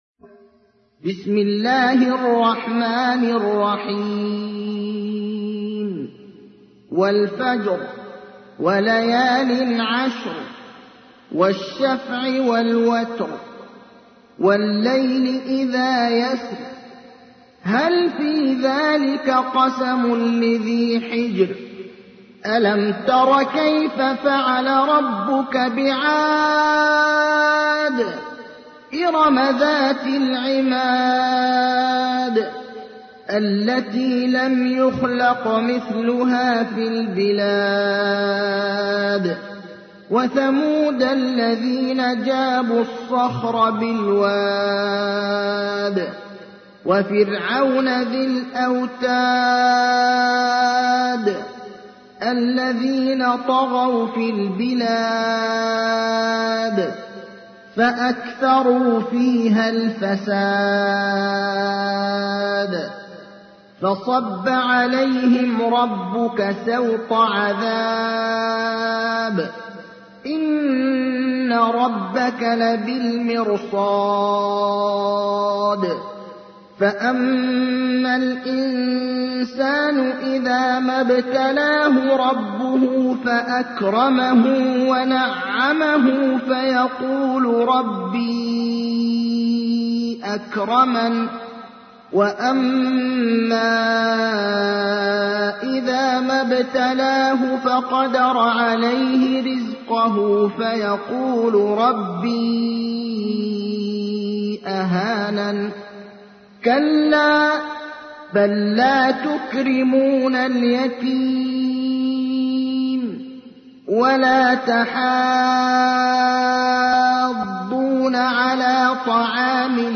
تحميل : 89. سورة الفجر / القارئ ابراهيم الأخضر / القرآن الكريم / موقع يا حسين